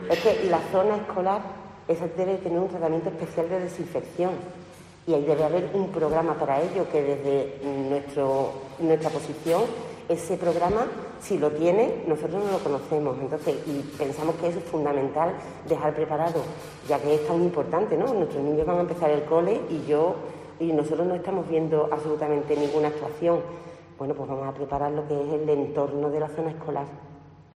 Pilar Marín, presidenta del PP en Huelva
Esta propuesta ha sido anunciada en una rueda de prensa en la que ha comparecido la presidenta del Grupo Municipal del PP en Huelva capital, Pilar Marín, quién ha instado al gobierno local a cambiar el rumbo y la deriva de la suciedad en Huelva Capital, por limpieza y mejora en el funcionamiento de los servicios municipales.